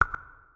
SFX_Confirmation_02.wav